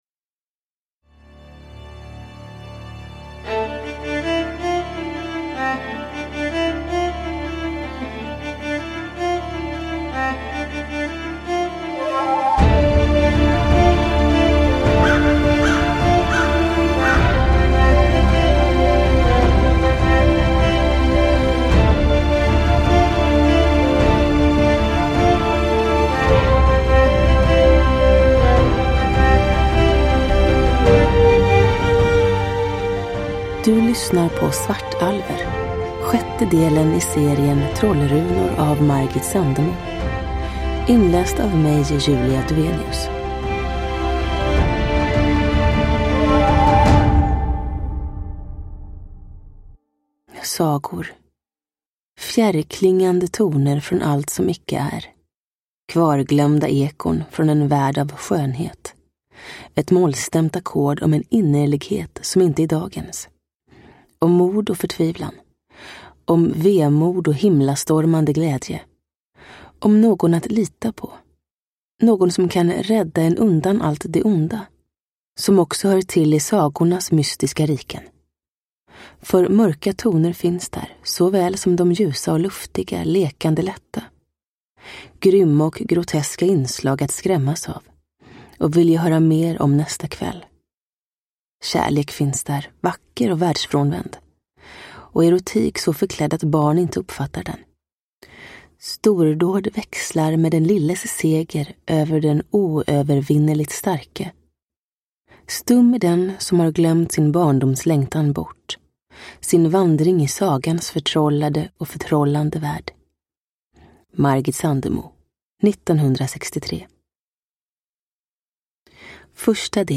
Svartalver – Ljudbok – Laddas ner
Uppläsare: Julia Dufvenius